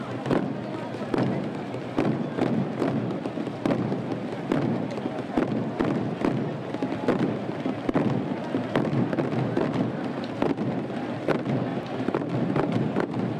Tambores roncos
Hdad. del Cristo Resucitado (Pitufos)